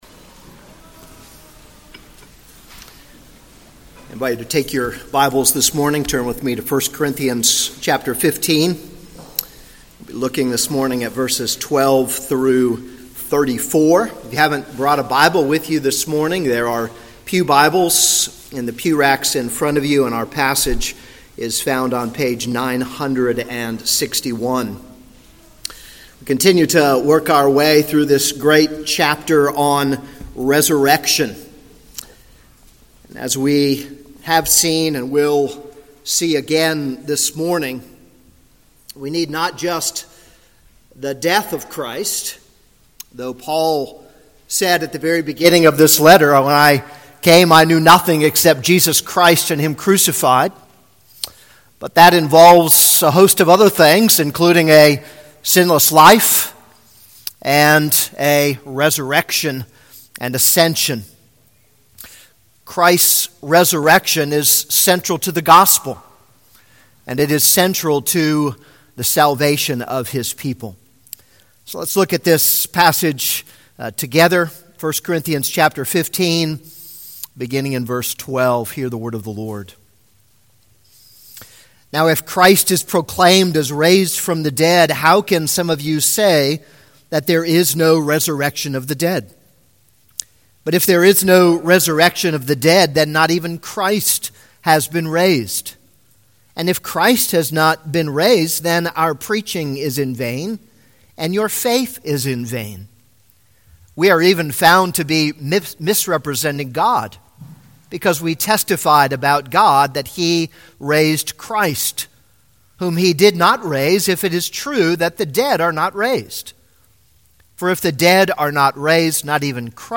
This is a sermon on 1 Corinthians 15:12-34.